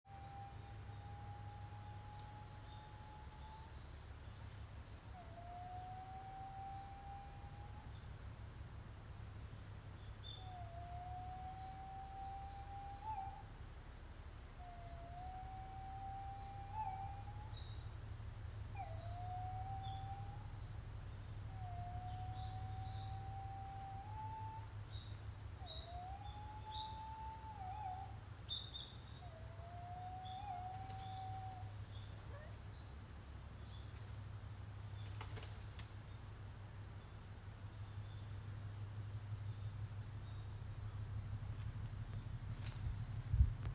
憩の森を歩いていると、尺八のような声が森から聞こえてきた。ズアカアオバトの鳴き声かもしれない。
憩の森で聞こえた声.m4a